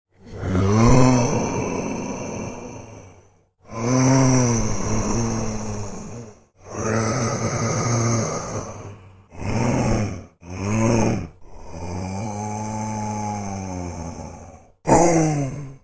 Sound Effects
Zombie Moans